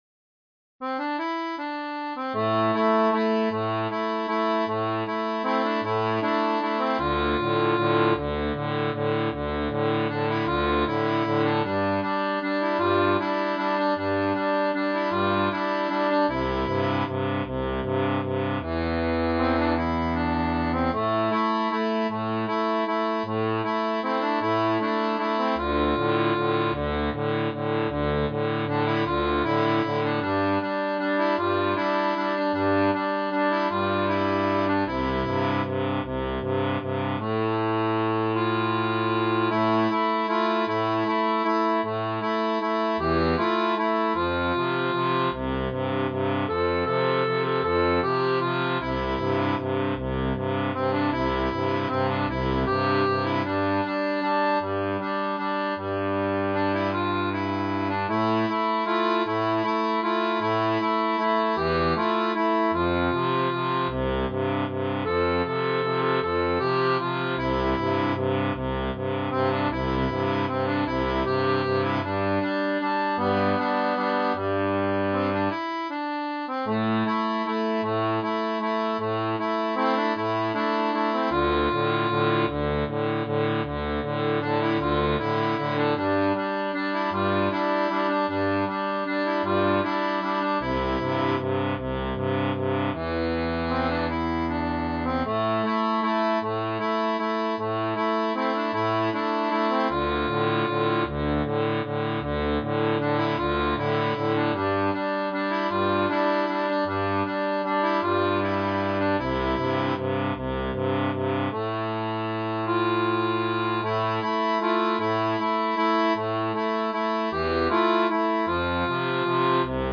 Pop-Rock